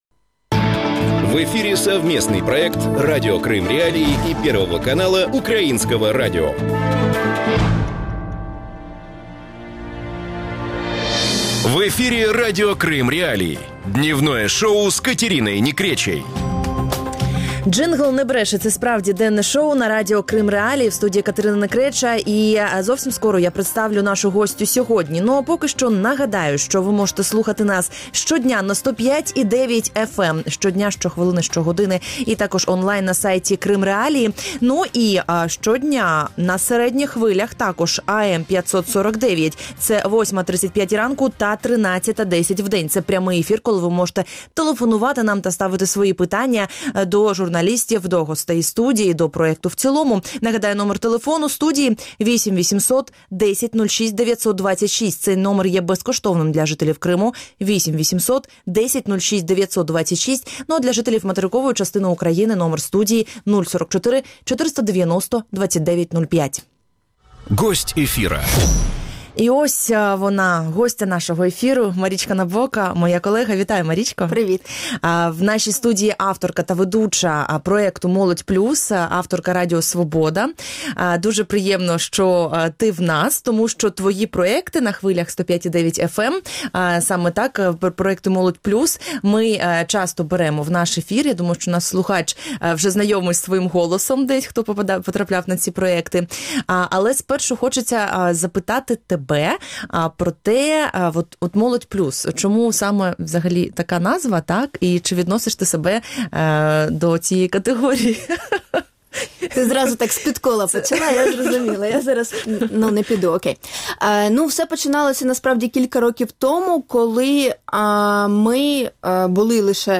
Об этом – в эфире Радио Крым.Реалии – проекте «Дневное шоу» с 12:10 до 12:40.